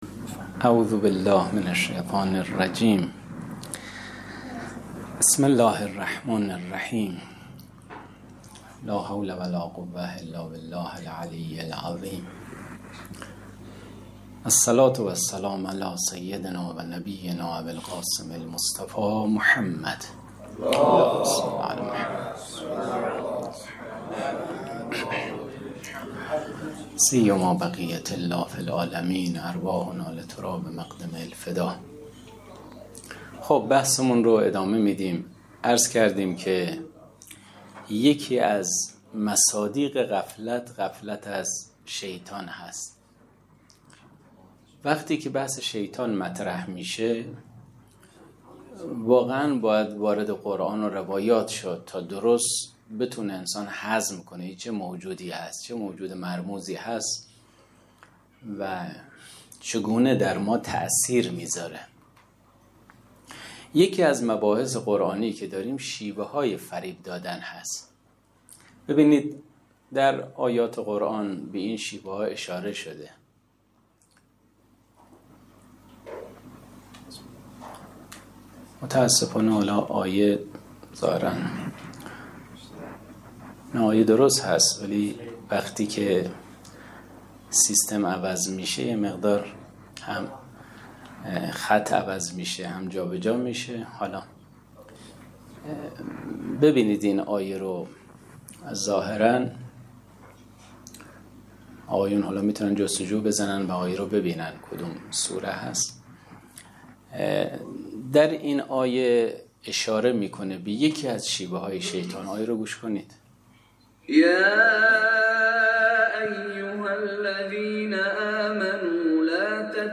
در ادامه، بخشی از زمان جلسه به پرسش و پاسخ طلاب درباره نکات تفسیری اختصاص یافت.